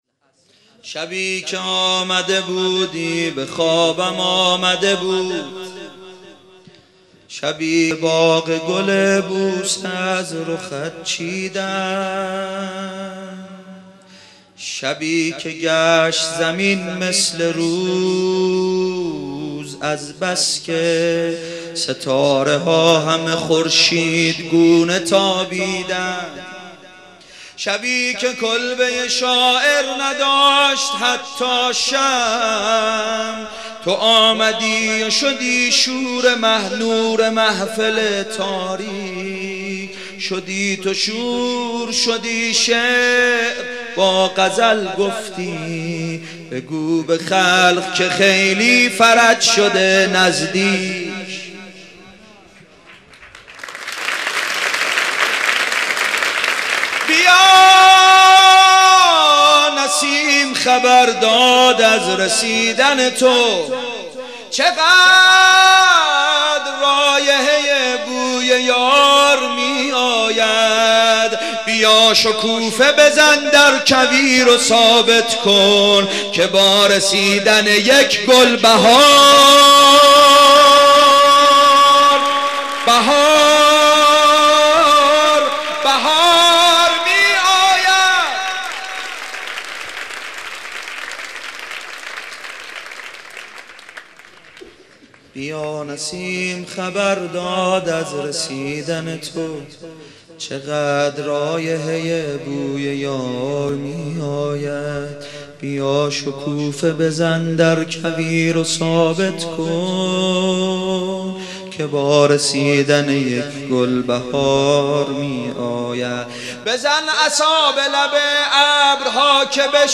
شب سوم رمضان 95، حاح محمدرضا طاهری